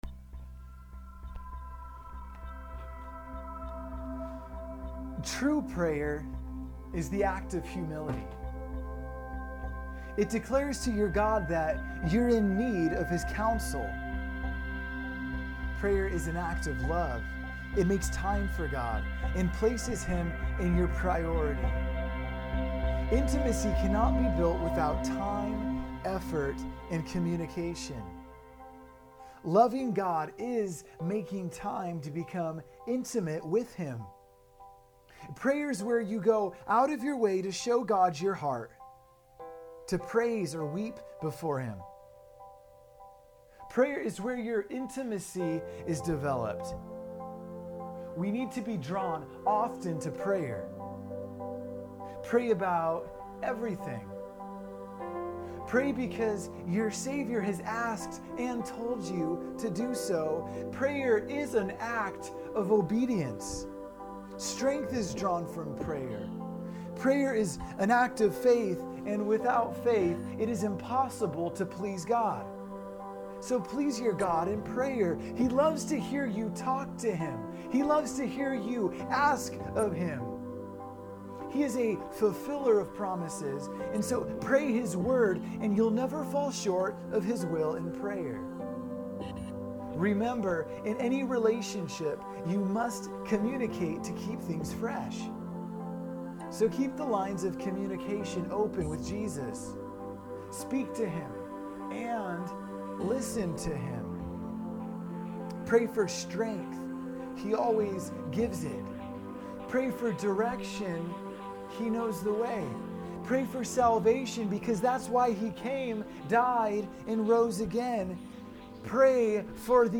Check out the Audio Book/Sermon-Jam by clicking Here Prayer or Here >Prayer!